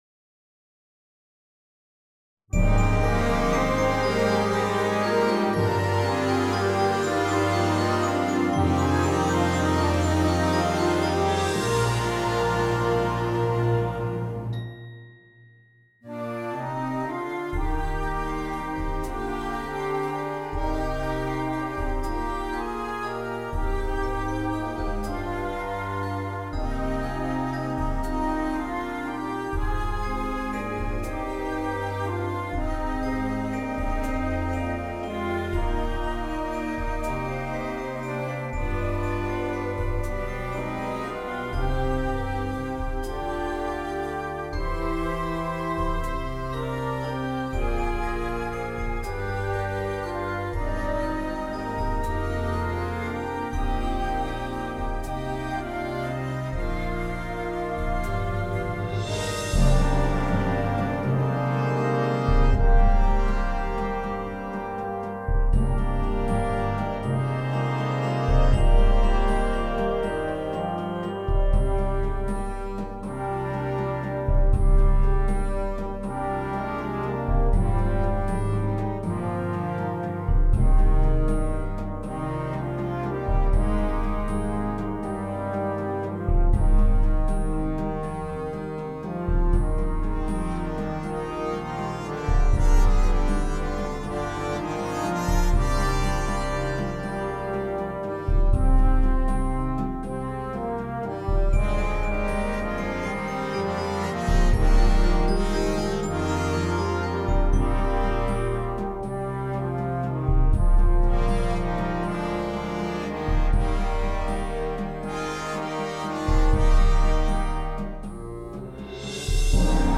Genre: Band
Alto Saxophone
B-flat Trumpet 1
Timpani [4 drums]
Percussion 1 [2 players]: Snare Drum, Bass Drum